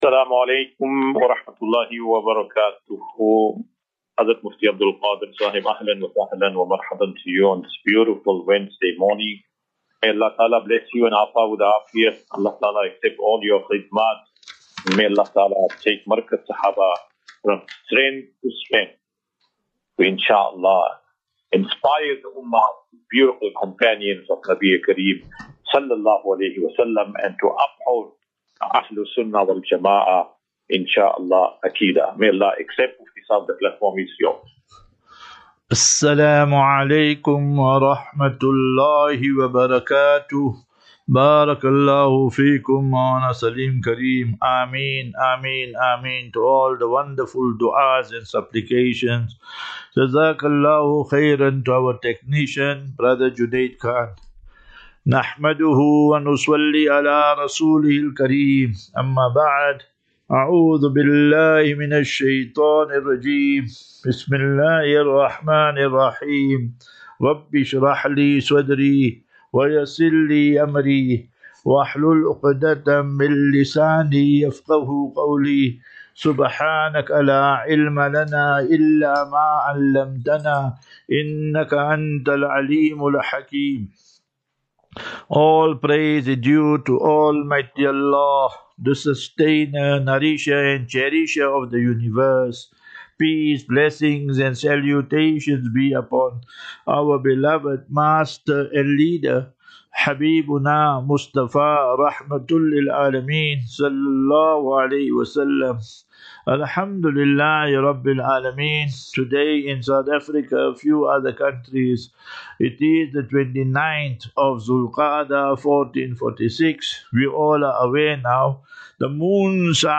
28 May 28 May 2025. Assafinatu - Illal - Jannah. QnA.